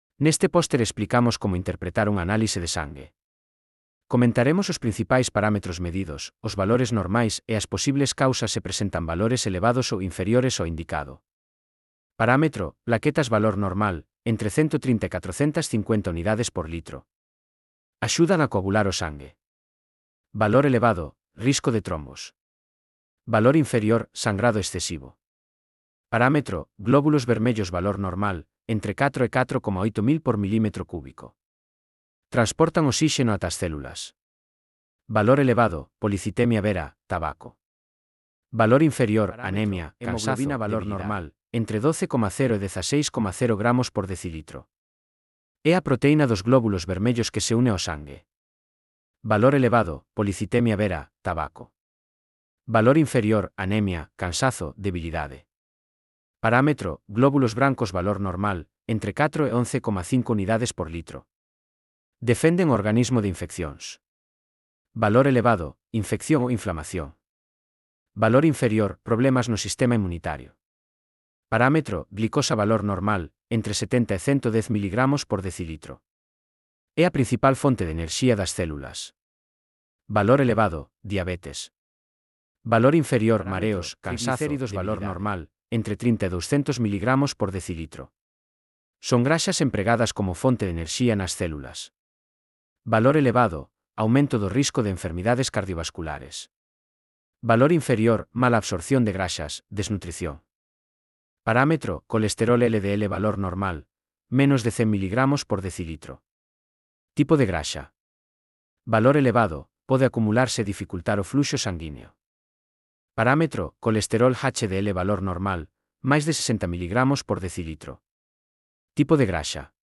Elaboración propia coa ferramenta Narakeet (CC BY-SA)